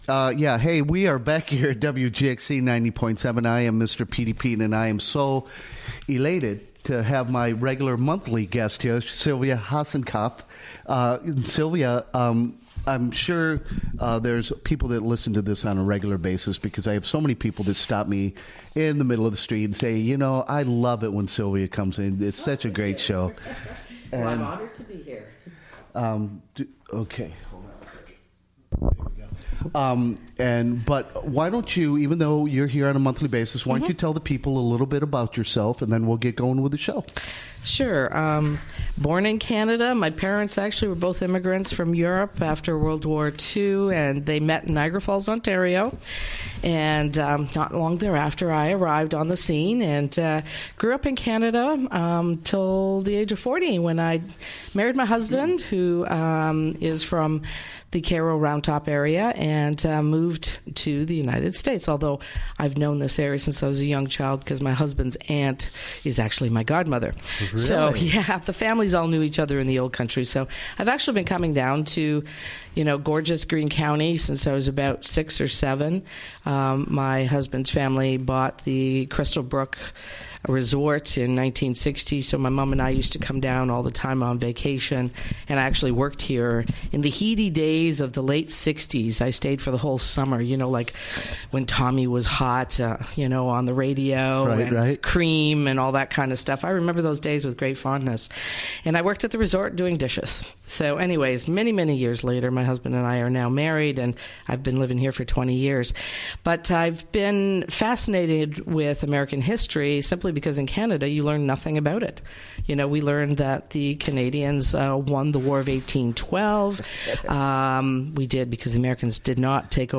Recorded live during the WGXC Morning Show, Jan. 23, 2018.